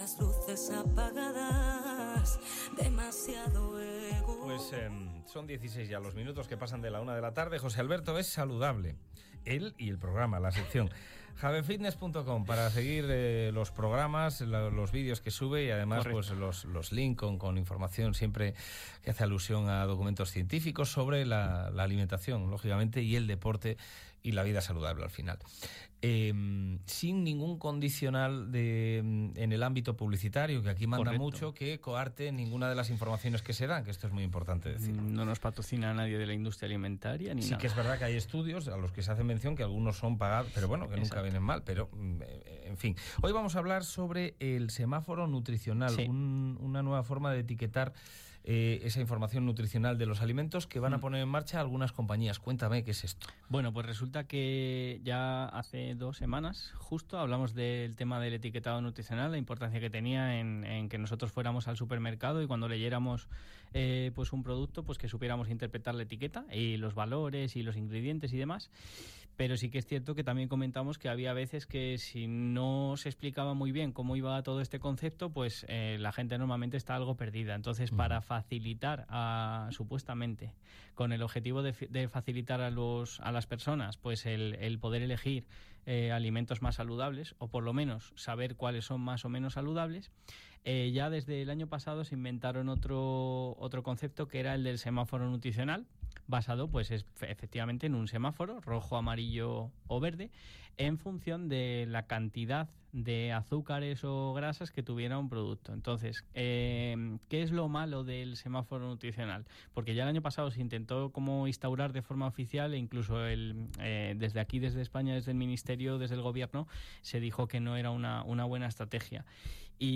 Hoy os traigo el centésimo vigésimo octavo programa de la sección que comenzamos en la radio local hace un tiempo y que hemos denominado Es Saludable.